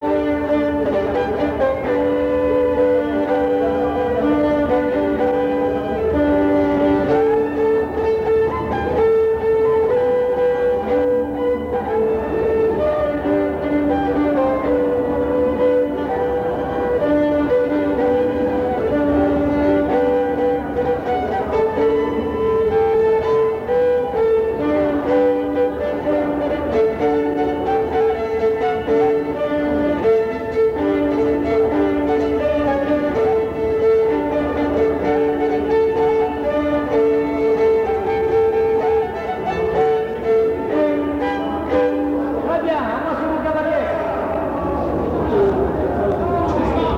Polka - La trompette en bois
4e air du pot pourri
danse : polka
Pièce musicale inédite